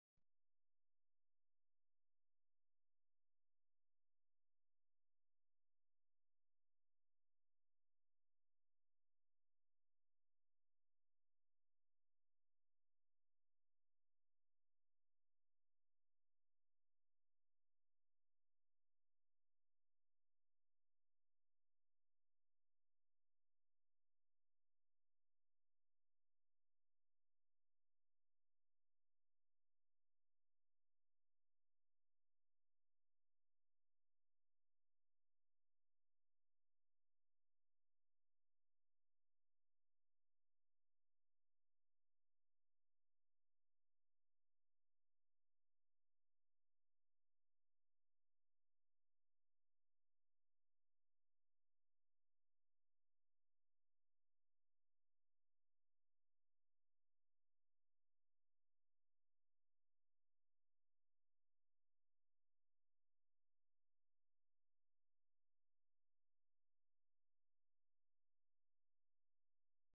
Hungry Starlings Sound Effects Free Download
hungry starlings